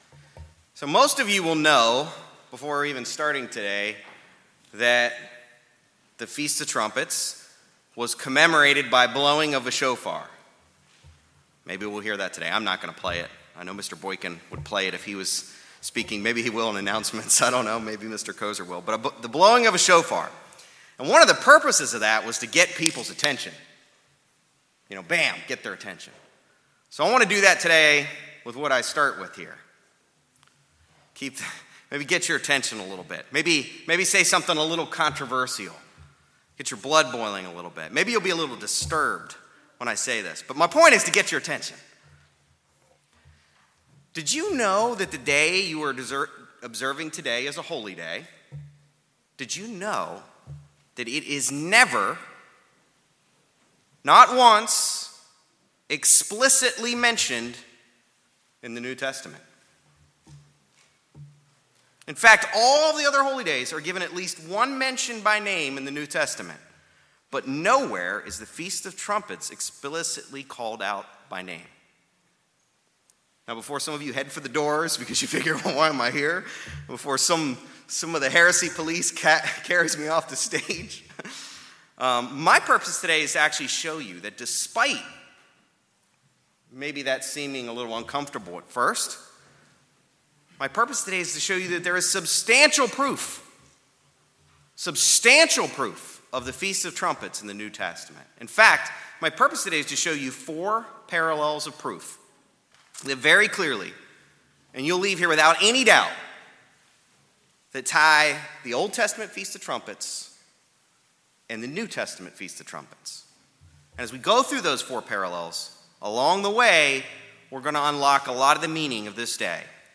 Sermons
Given in Raleigh, NC